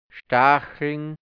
Mundart-Wörter | Mundart-Lexikon | deutsch-hianzisch | Redewendungen | Dialekt | Burgenland | Mundart-Suche: A Seite: 15